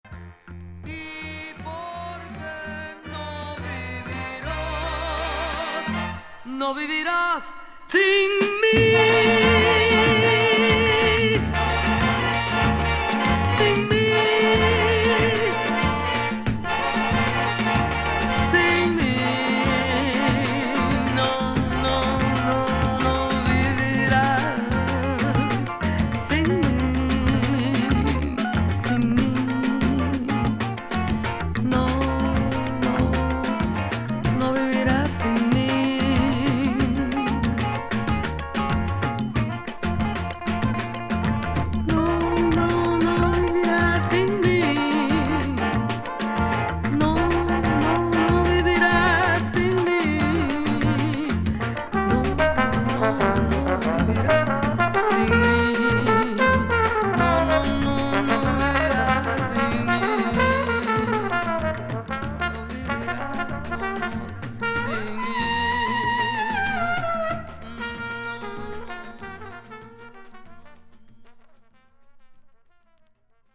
1950年代キューバでのフィーリン/ボレロを代表する女性シンガー
本作品は、1974年頃に制作されたボレロやCANCIONが収録されている。